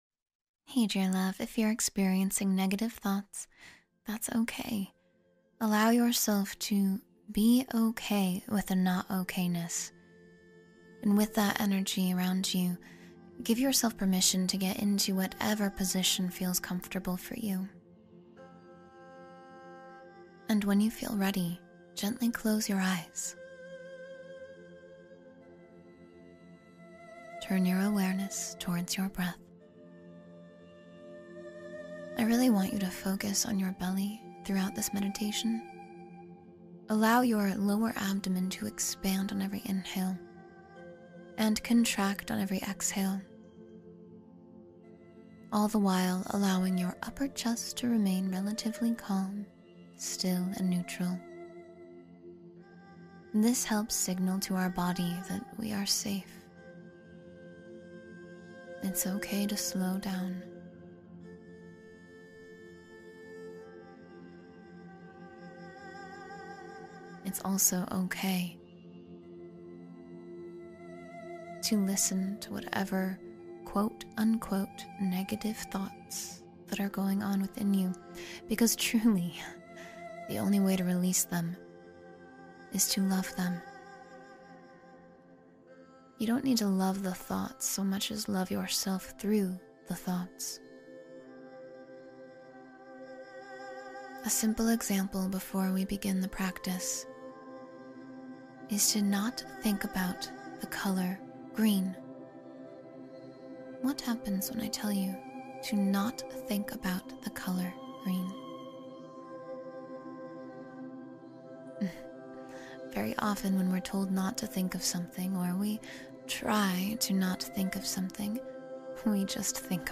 Release Negative Thoughts and Let Light In — Guided Meditation